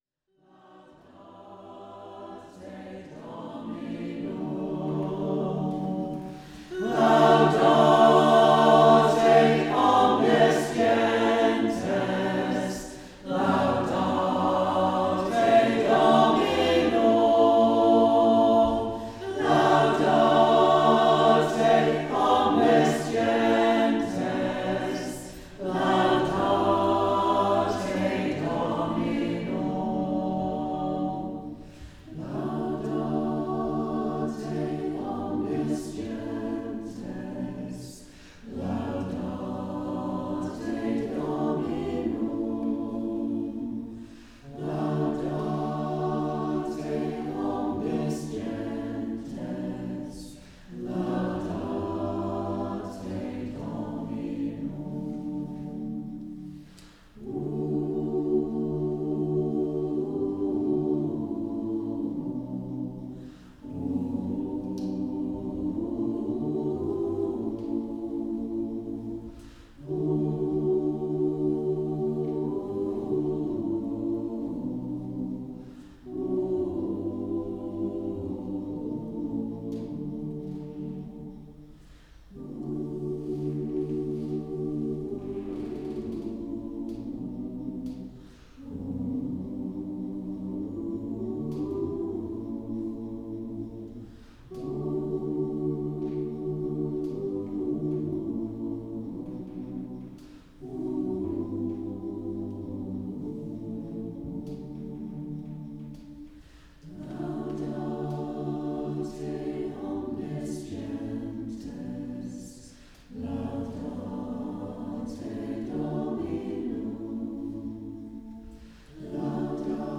Please note: These are stereo master files that are full CD quality (16 bit / 44.1 kHz) or higher.
Choir – Laudate Dominum
Local choir rehearsing – recorded 16th May 2018